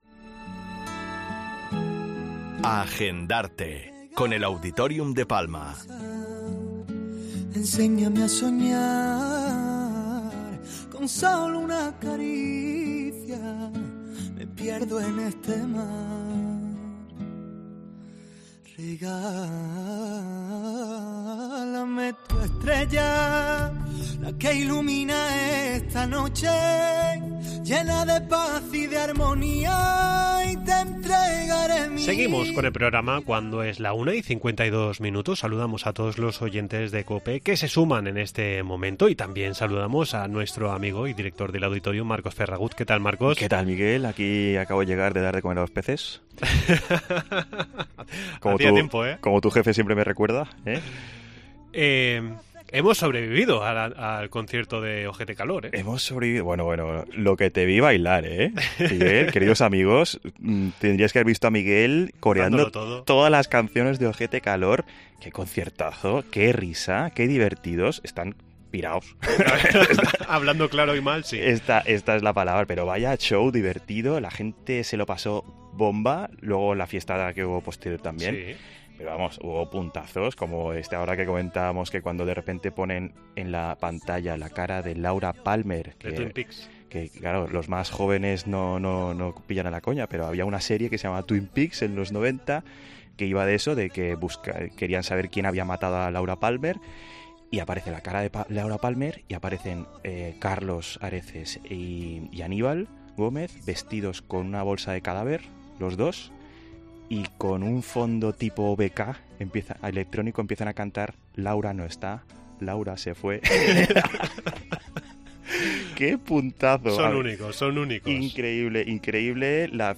Entrevista en 'La Mañana en COPE Más Mallorca', jueves 20 de julio de 2023.